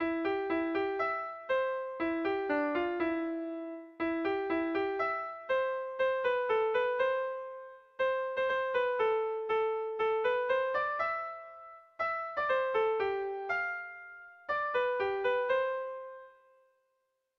Melodías de bertsos - Ver ficha   Más información sobre esta sección
A1A2BD